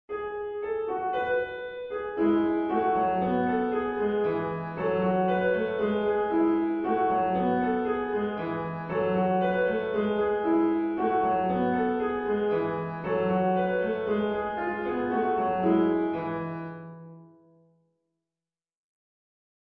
リピートは基本的に省略しています